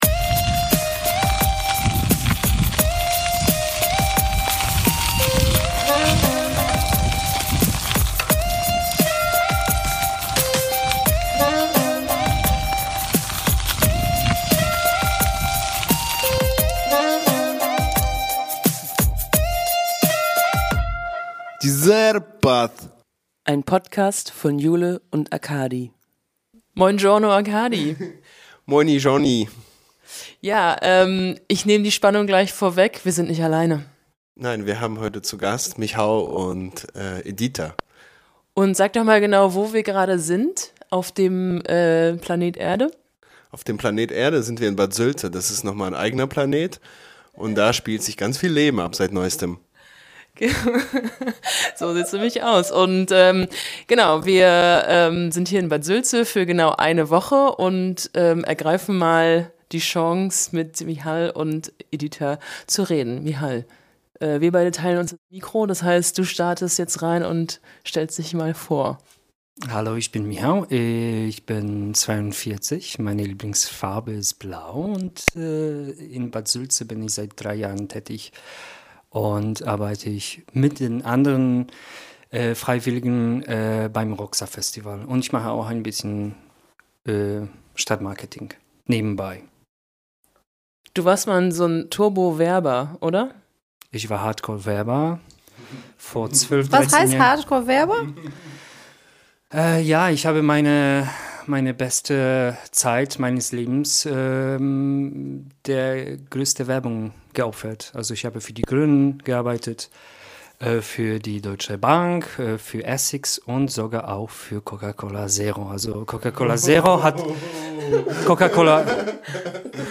Neuer Name, neues Intro, let's go.
Die Beiden haben wir in Bad Sülze erwischt, im Rahmen des Roxsa Festivals.